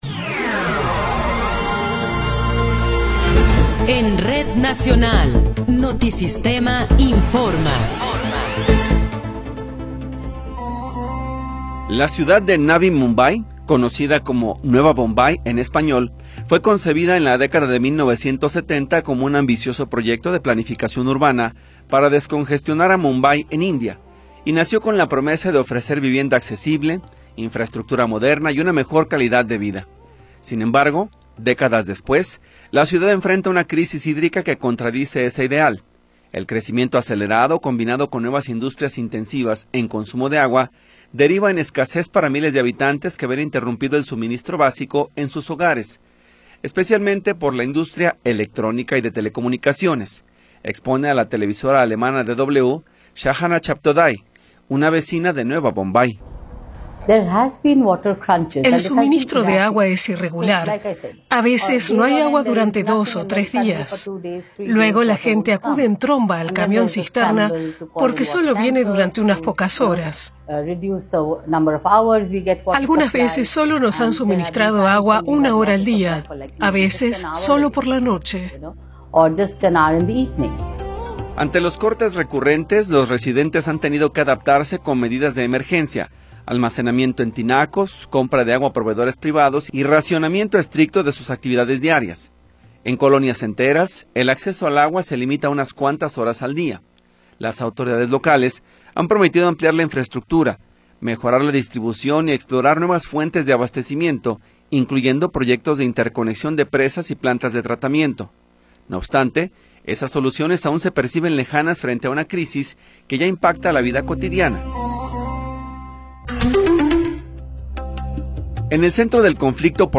Noticiero 16 hrs. – 19 de Abril de 2026